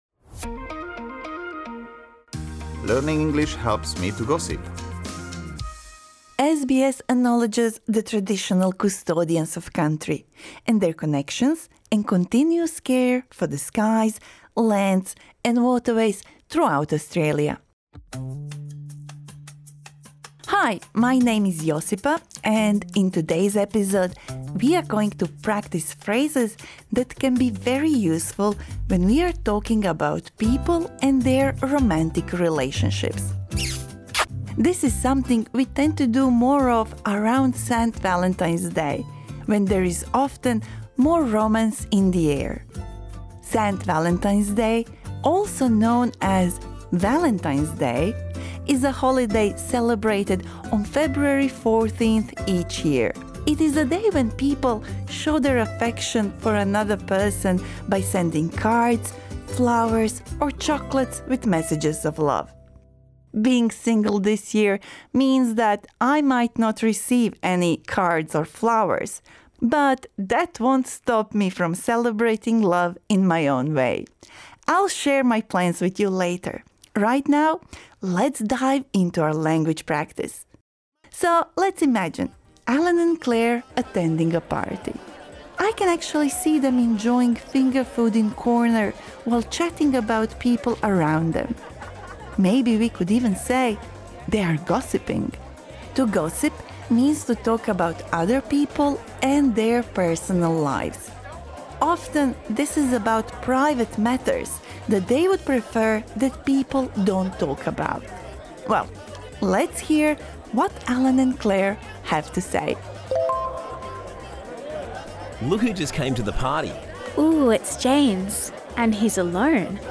This lesson suits intermediate learners.